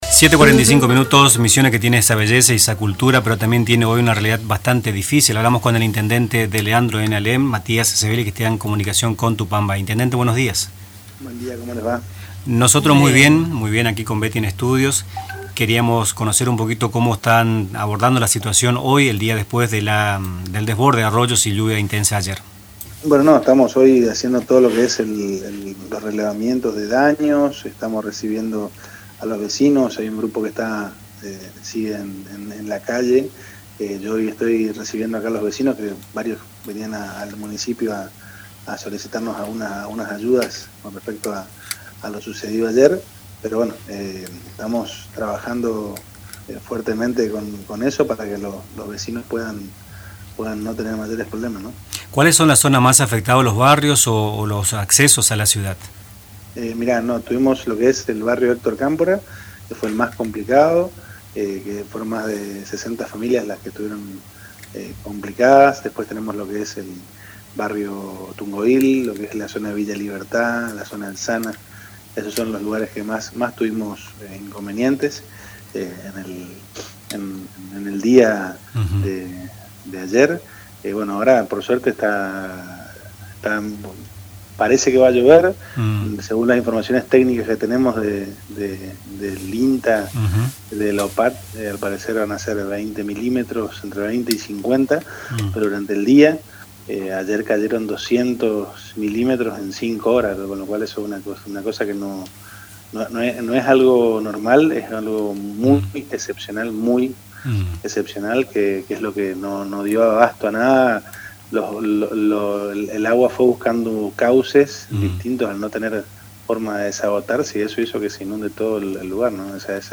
En una comunicación con Radio Tupa Mbae, el Intendente de Leandro N. Alem, Matías Sebely, expuso la compleja situación que atraviesa el municipio luego del desborde de arroyos y la lluvia intensa que azotó la zona ayer, jueves 2 de mayo.